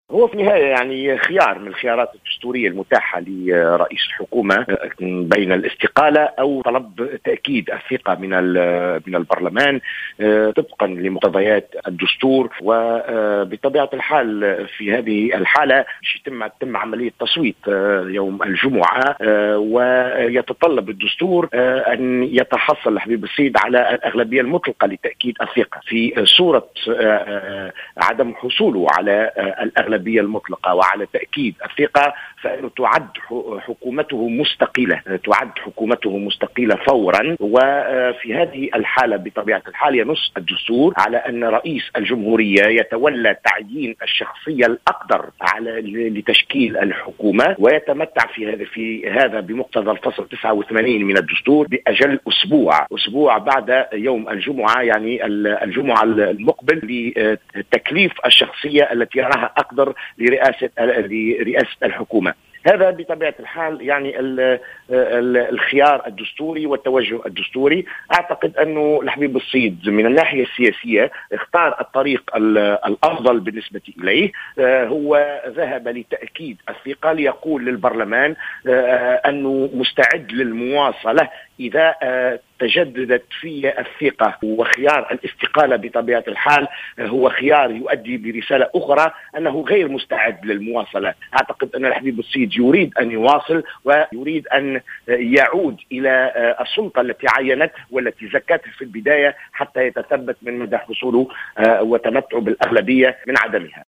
au micro de Jawhara Fm